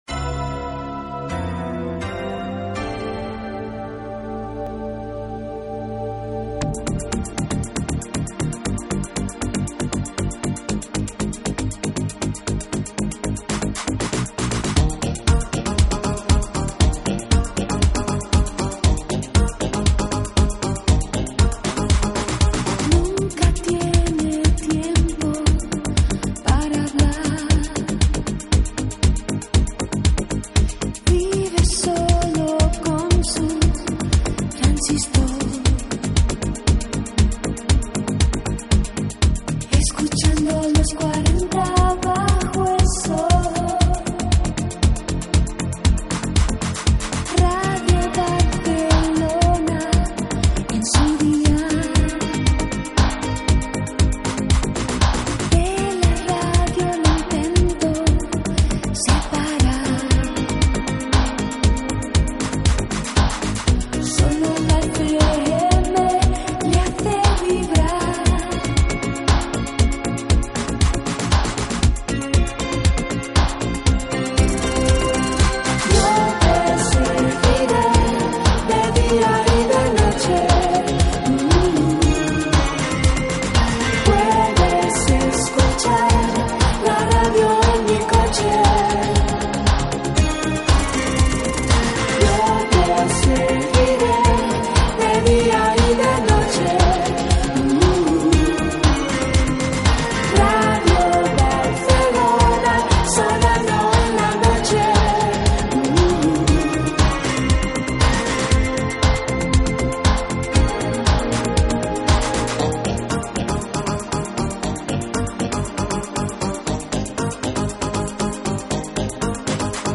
Cançó